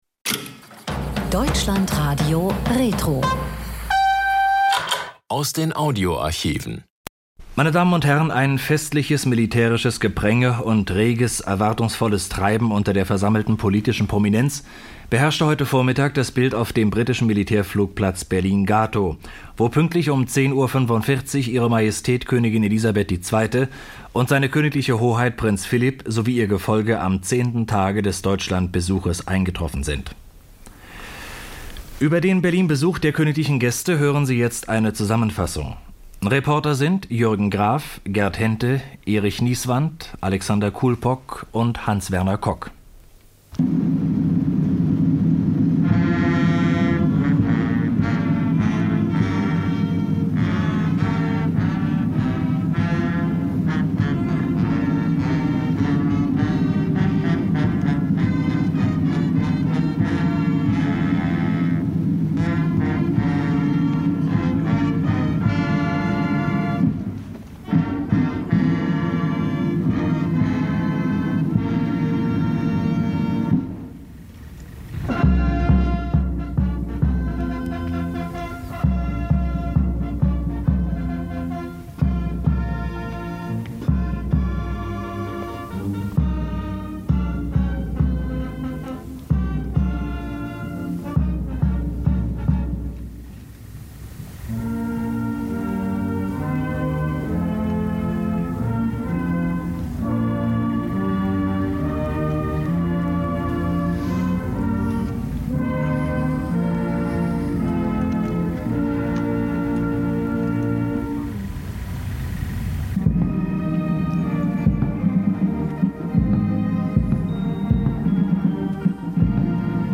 RIAS-Bericht zum Staatsbesuch von Königin Elizabeth II. und Prinz Philip in Berlin: Ankunft, Stadtrundfahrt und Empfang durch Bürgermeister Willy Brandt.